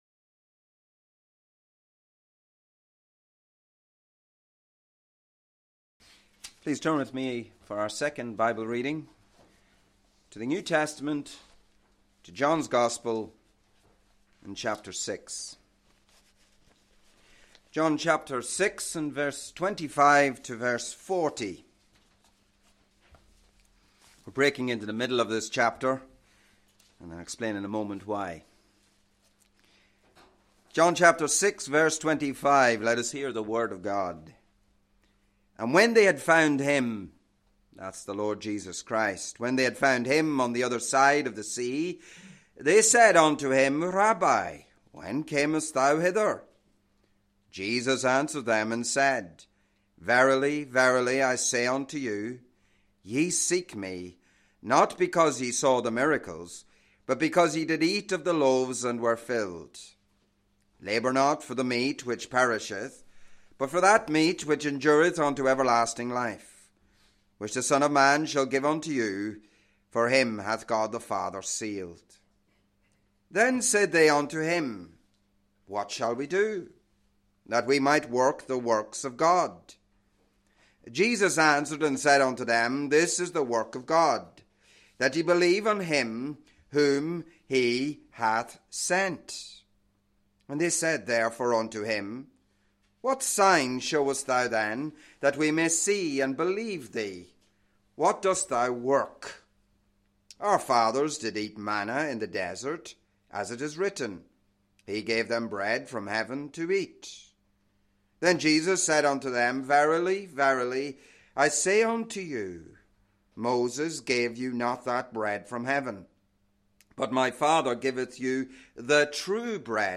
The "I AMs of Christ" a series of sermons